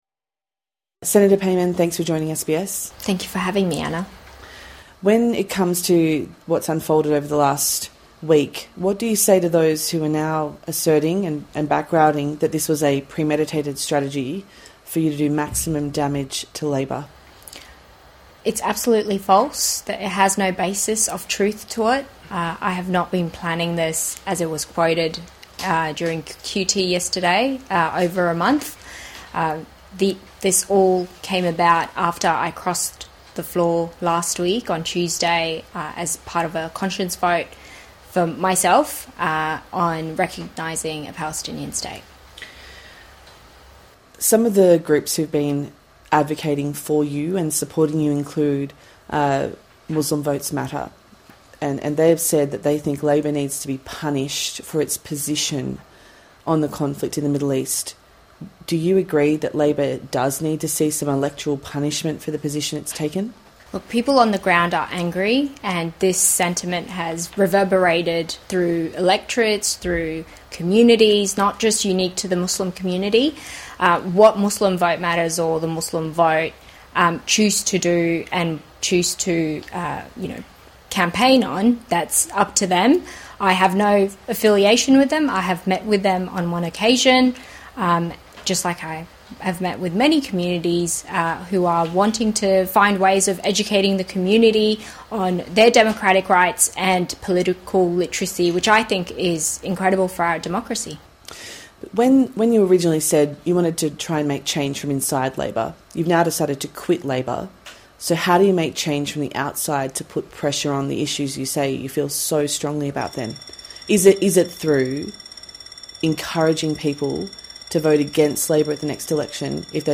INTERVIEW: Senator Fatima Payman speaks to SBS